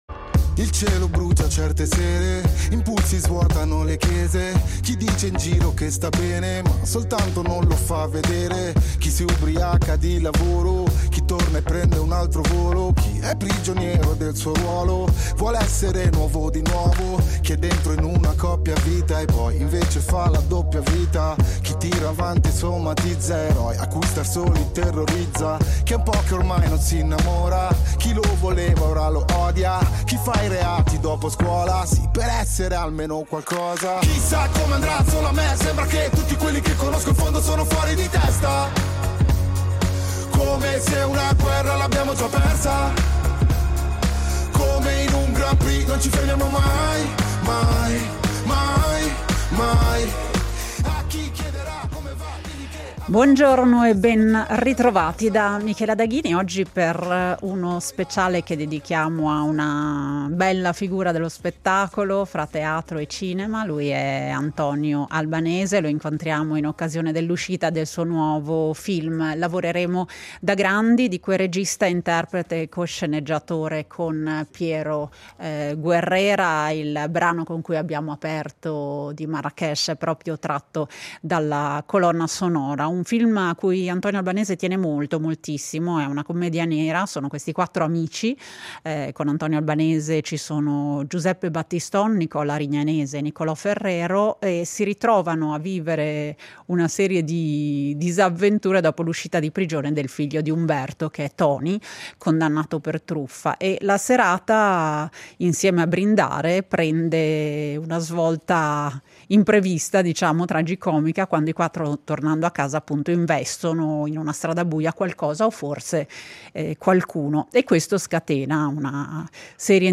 Ospite a Rete Uno, Antonio Albanese ci racconterà il suo sguardo sulla vita, umano e sempre capace di toccare corde profonde.